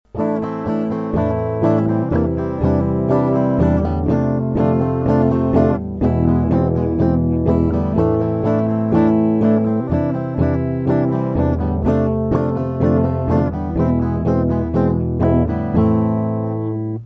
Точнее в проигрыше чередование этих аккордов: